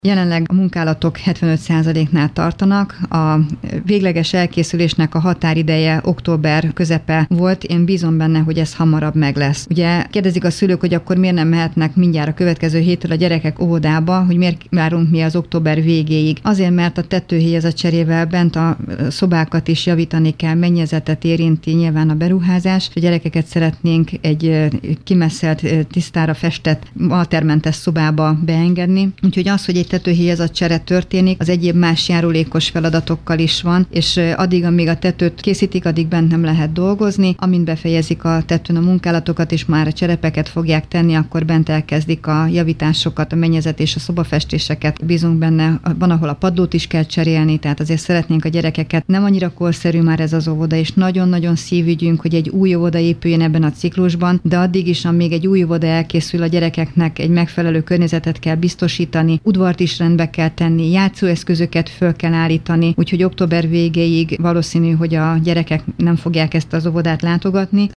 Pánczél Károly országgyűlési képviselő erről is beszélt rádiónknak.